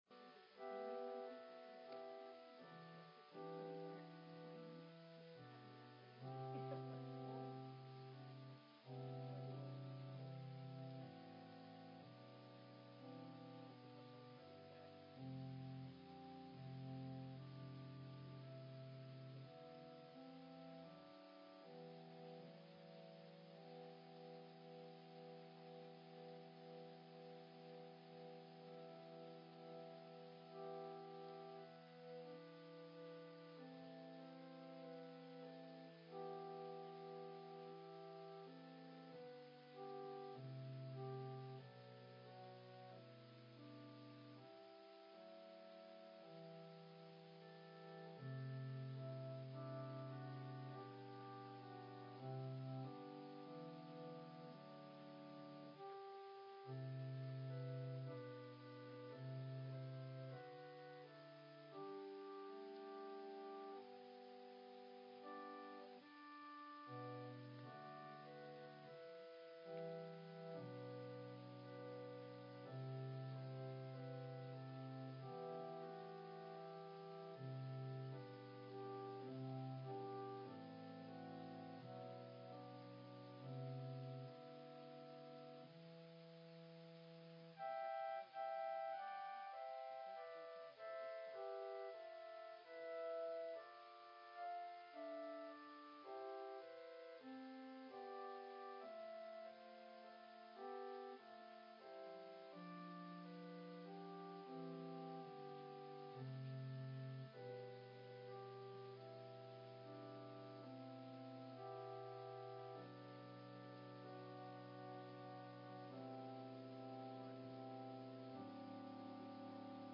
Quiet Christmas Service 2023